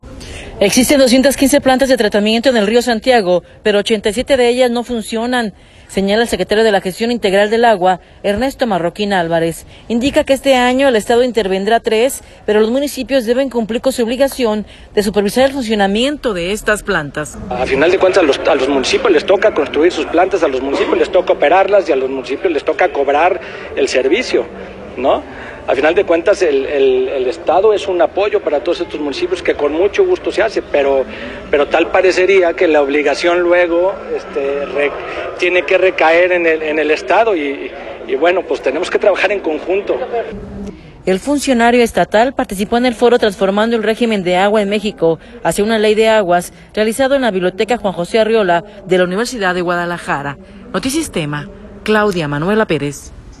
El funcionario estatal participó en el foro Transformando el Régimen del Agua en México: Hacia una Ley de Aguas, realizado en la Biblioteca Juan José Arreola de la Universidad de Guadalajara.